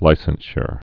(līsən-shər, -shr)